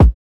Kick (COFFEE BEAN)(1).wav